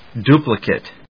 音節du・pli・cate 発音記号・読み方
/d(j)úːplɪkət(米国英語), djúːúːplɪkət(英国英語)/